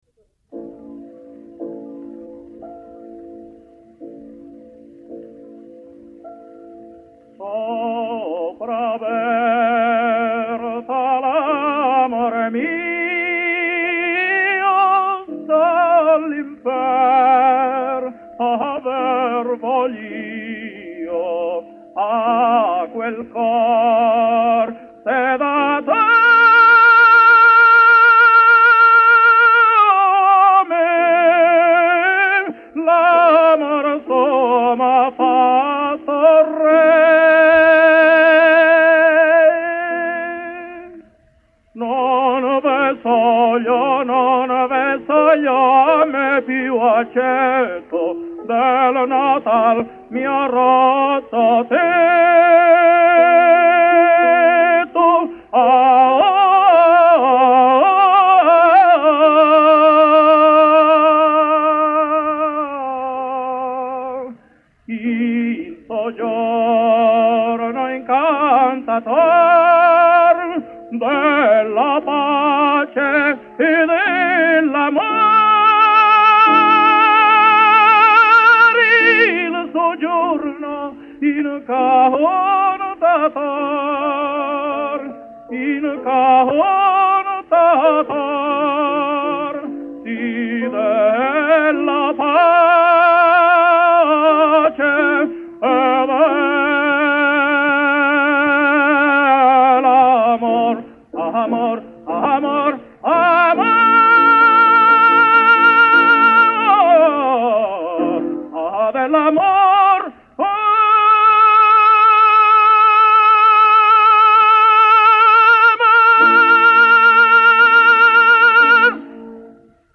Spanish Tenor.